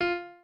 pianoadrib1_43.ogg